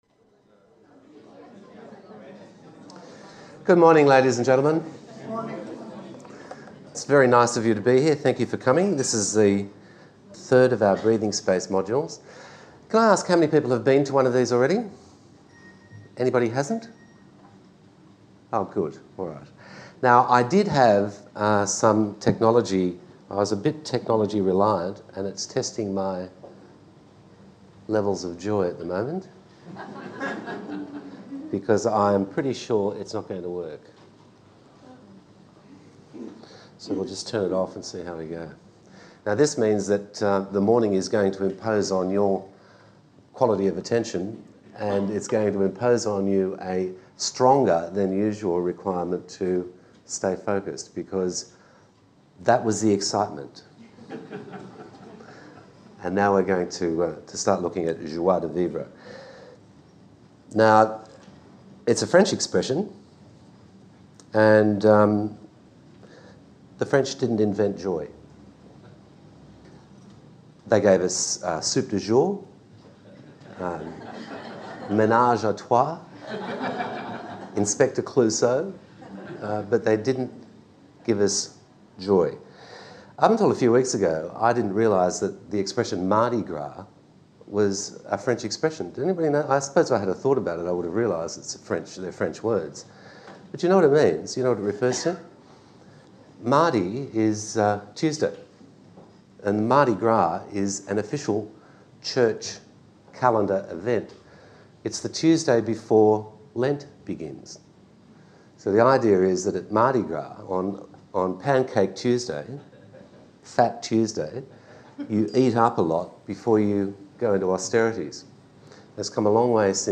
Breathing Space A series of talks on subjects that matter
Joie-de-VivrePart-1-Talk.mp3